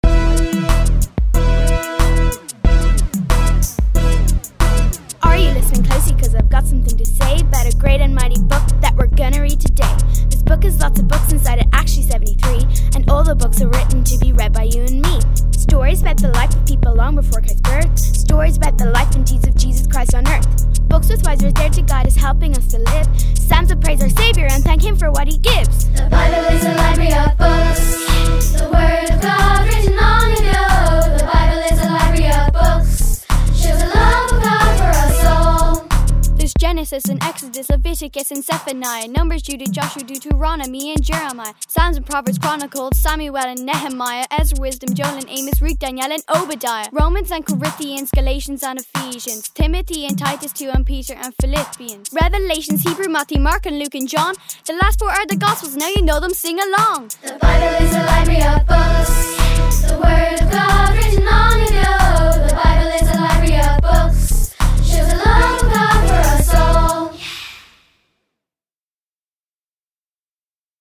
1. Bible Rap